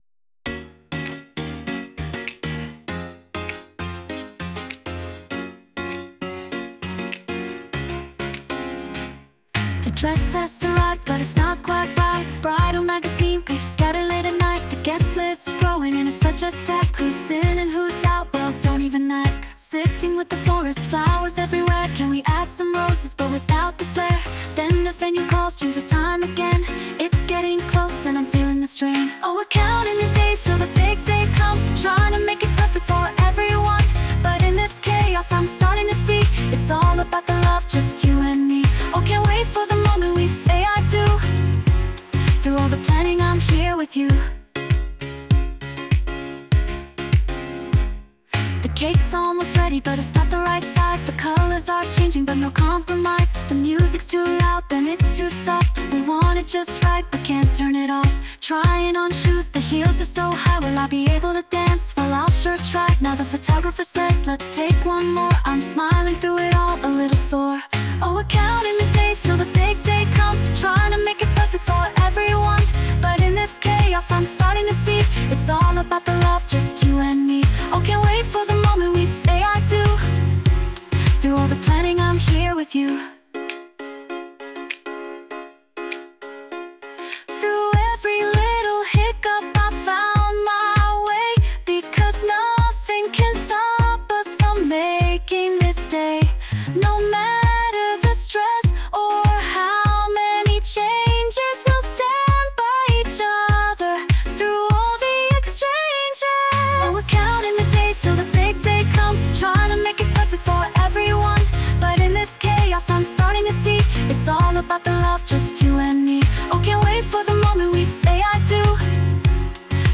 洋楽女性ボーカル著作権フリーBGM ボーカル
女性ボーカル（洋楽・英語）曲です。
曲調もまた今までと少し違った感じになっていると思います♪♪